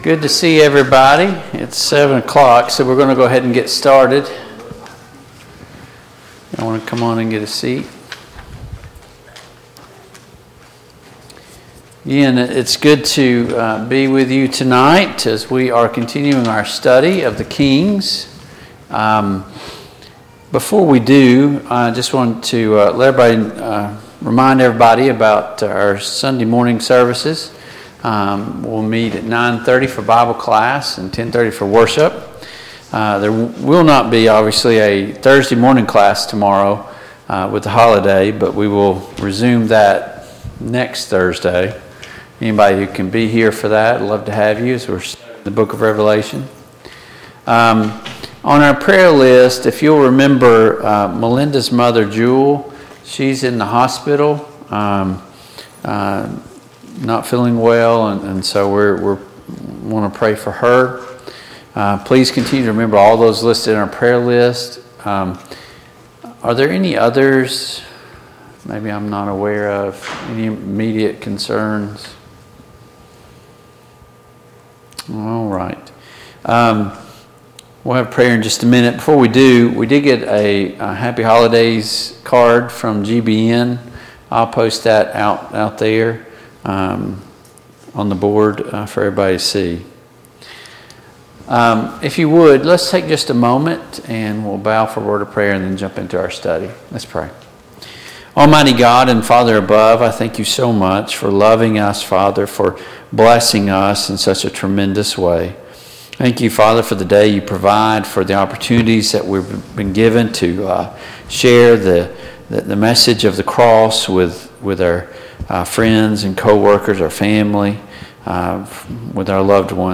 Passage: I Samuel 23-25 Service Type: Mid-Week Bible Study Download Files Notes « The End of Time What is the proper way of Interpreting the Scriptures?